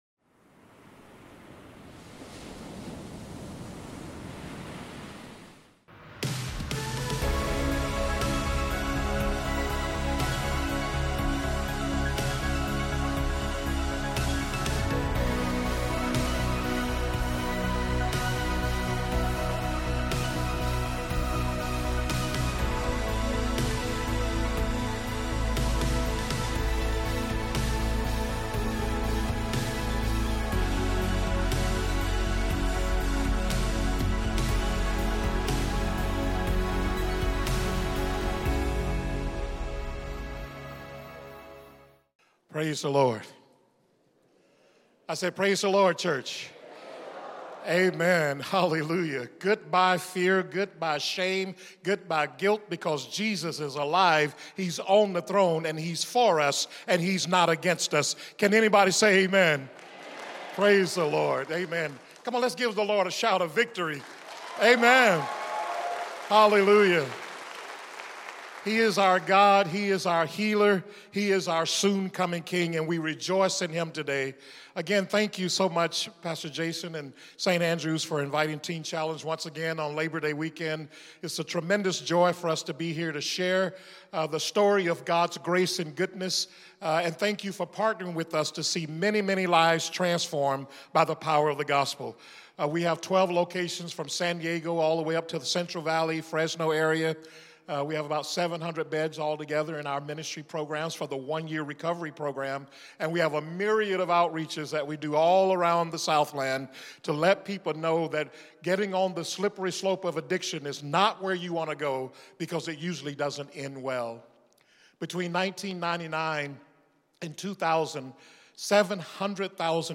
One-Off Sermon: September 3, 2023